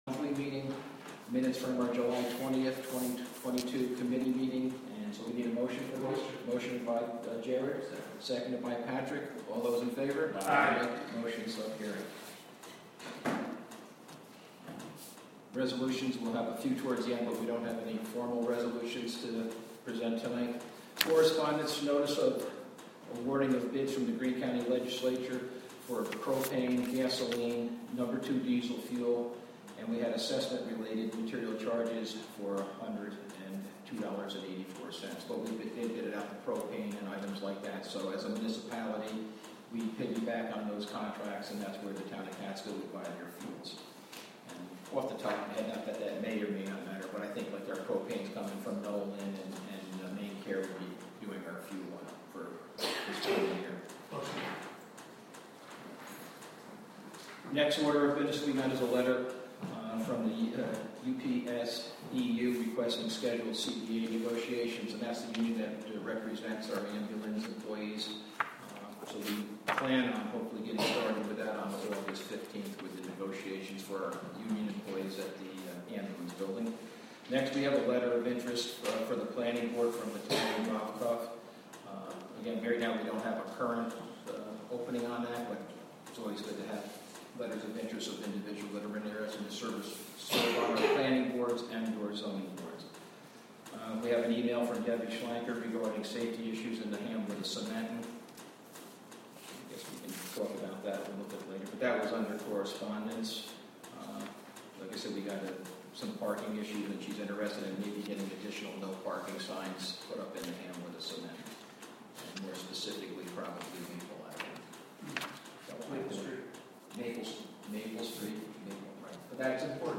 Live from the Town of Catskill: August 2, 2022 Town Board Meeting (Audio)